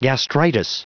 Prononciation du mot gastritis en anglais (fichier audio)
Prononciation du mot : gastritis